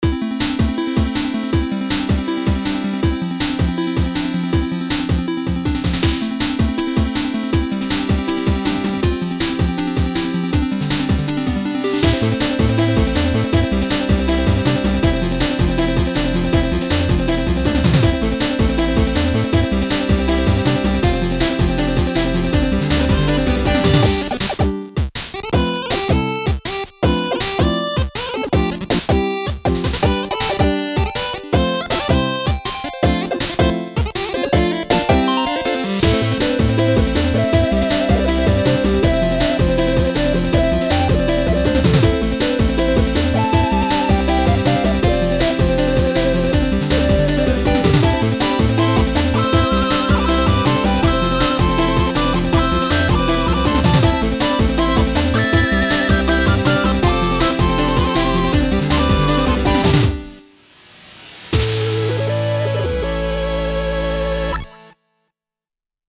But yeah it's pretty motivating and like 'wooah dude'